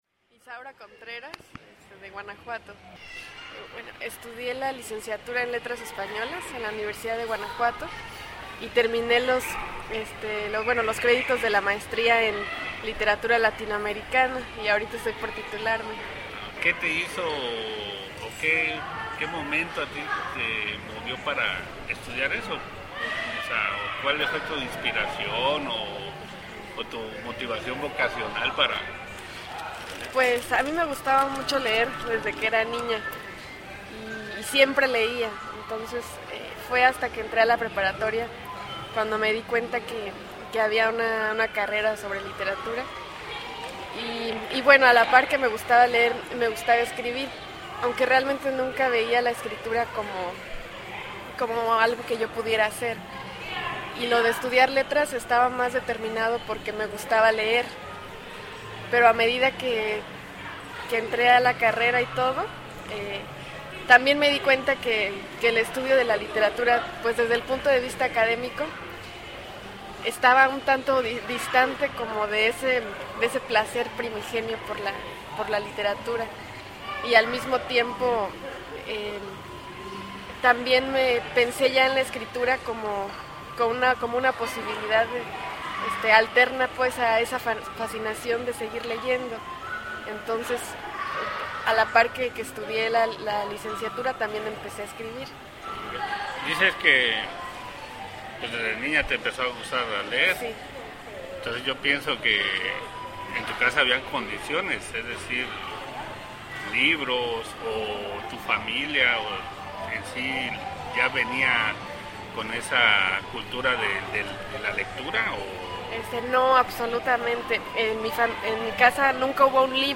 Lugar: Cafetería Biocafe, Tuxtla Gutierrez. Fecha: Agosto de 2010 Equipo: Grabadora Sony ICD-UX80 Stereo, Micrófono de construcción casera ( más info ) Fecha: 2010-12-05 06:21:00 Regresar al índice principal | Acerca de Archivosonoro